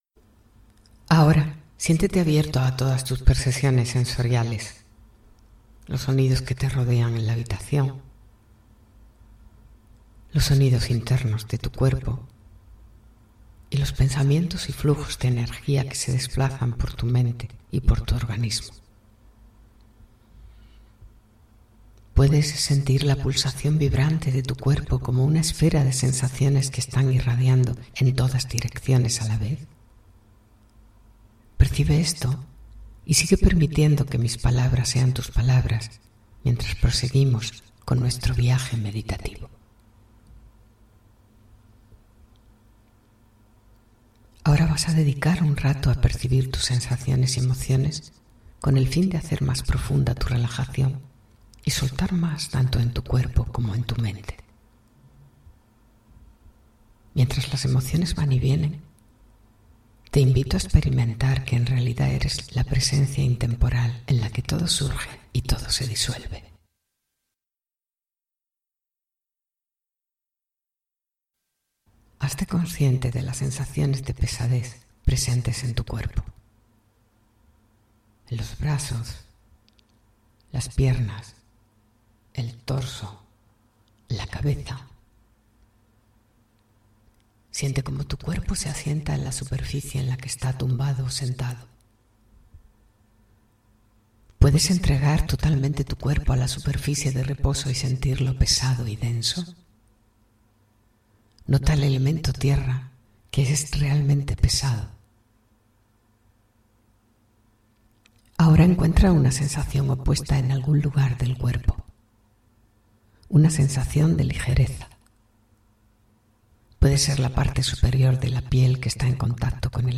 Yoga Nidra: Meditación Percibir sensaciones
3-YOGA_NIDRA_Percibir_sensaciones.mp3